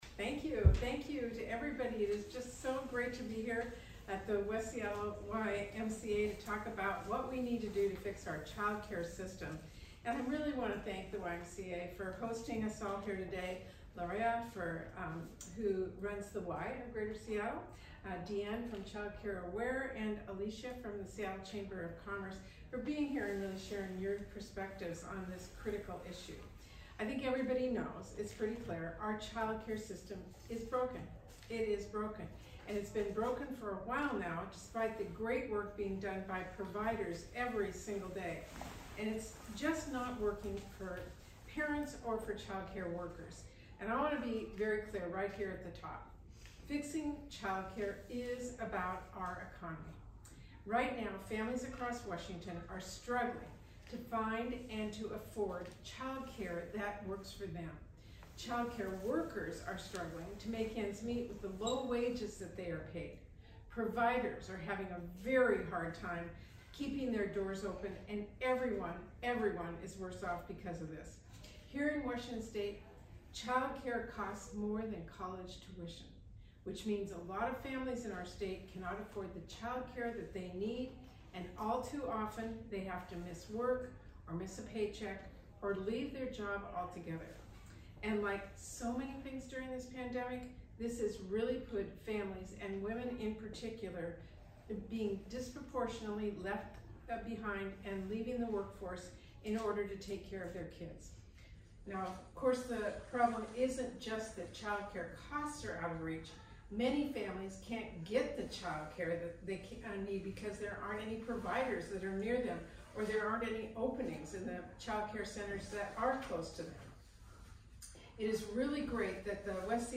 *** PHOTOS AND B-ROLL OF THE EVENT HERE *** ***AUDIO OF THE EVENT HERE *** (Seattle, WA) – Today, U.S. Senator Patty Murray (D-WA), Chair of the Senate Health, Education, Labor, and Pensions (HELP) Committee, visited a West Seattle child care center, where she outlined her plan to dramatically lower child care costs for working families and help get parents back to work.